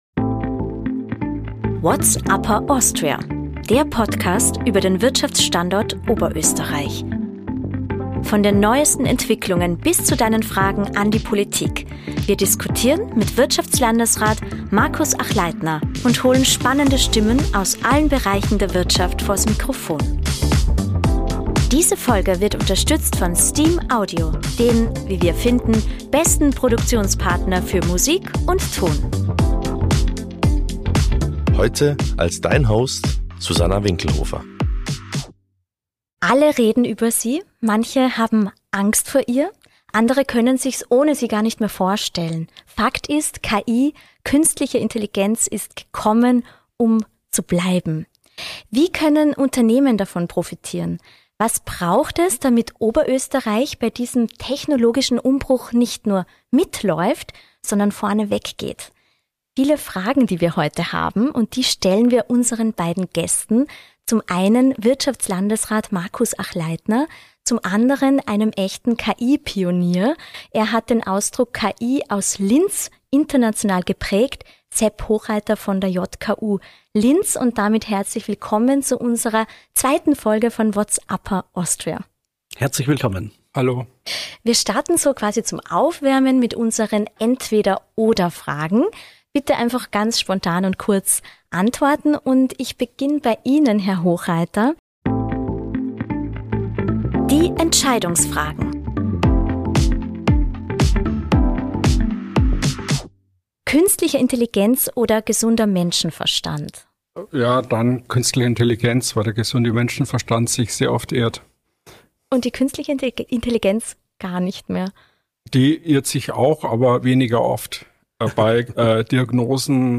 Die beiden stellen sich einer Reihe an Entweder-Oder-Fragen und reagieren auf die Schlagzeile des Monats sowie Hörerfragen aus der Community.